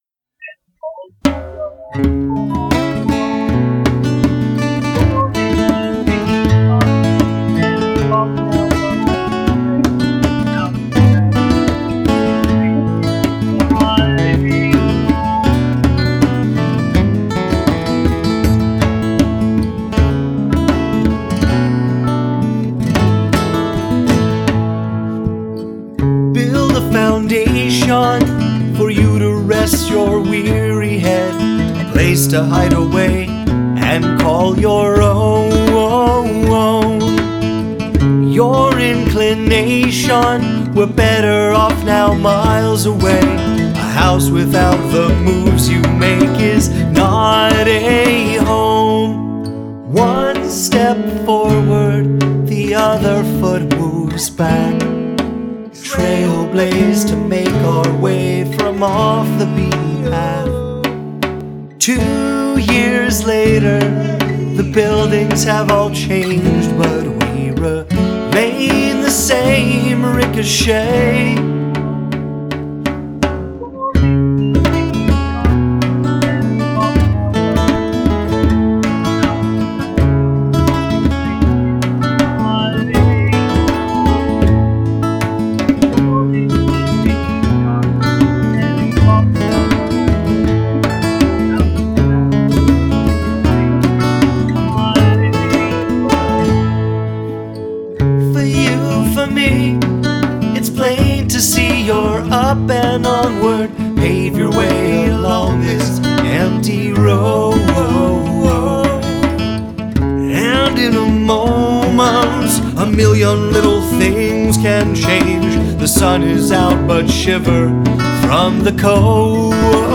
the acoustic folk/funk/blues music
singer / songwriter / guitarist / harmonica player